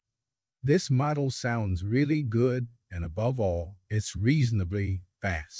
example_generated_output.wav